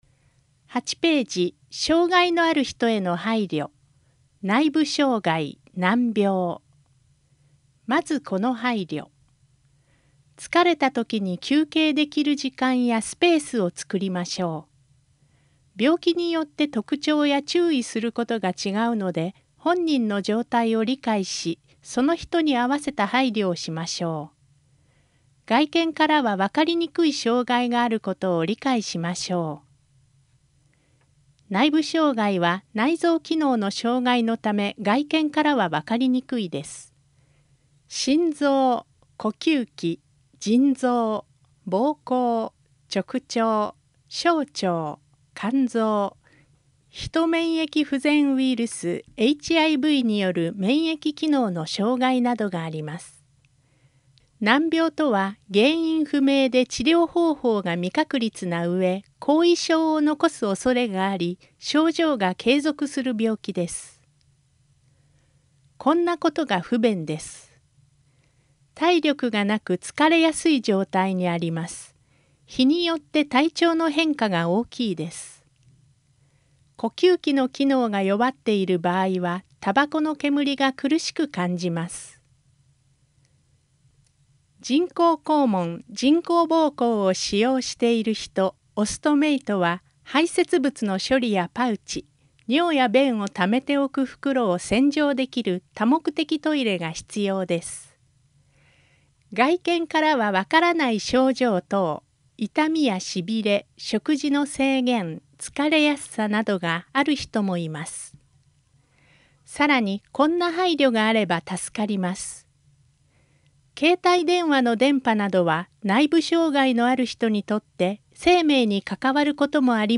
条例啓発用パンフレット（音訳版）